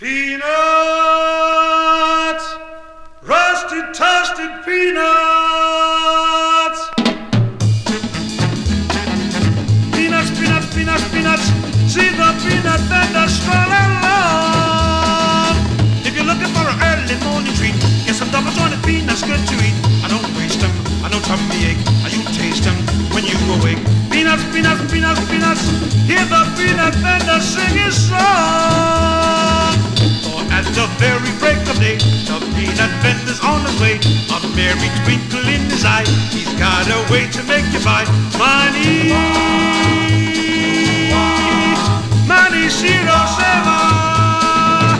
笑みが溢れるコーラス、チキチキドンドンリズムにオルガンに口笛などと軽快に、ラテンビートが心地良い好盤。
Pop, Vocal, Limbo　USA　12inchレコード　33rpm　Mono